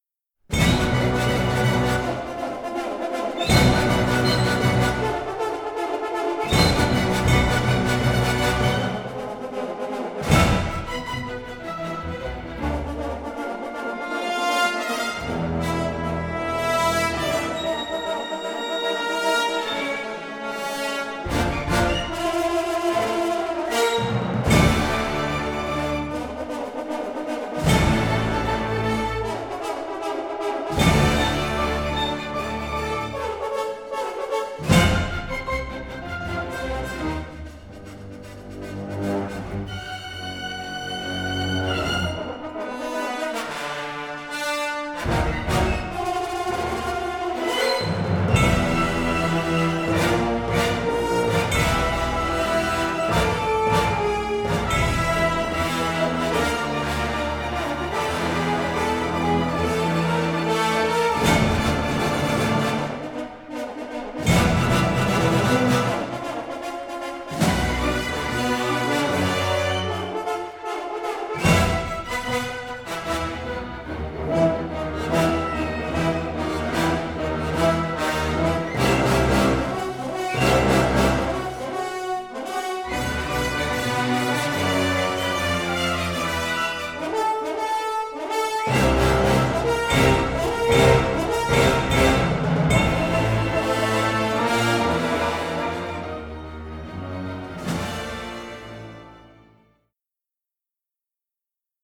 Both scores were recorded in January 2023